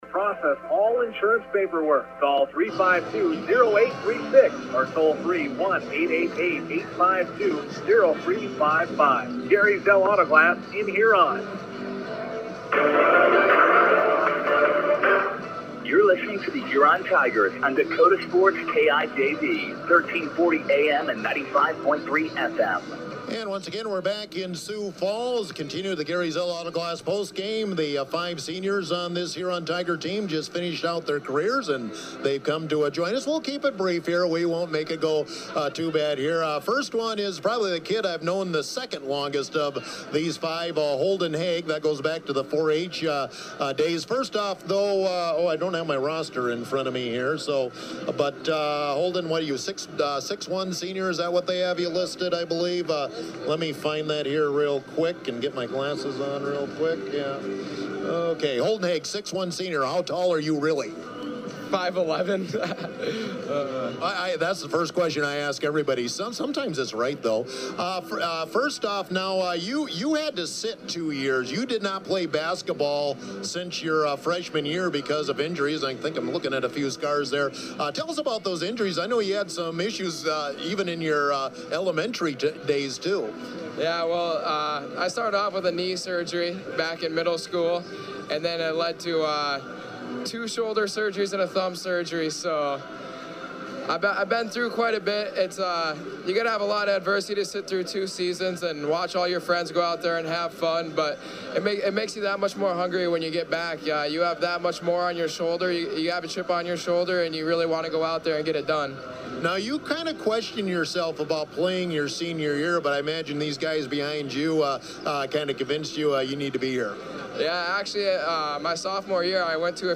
Tigers Senior Boys Interviews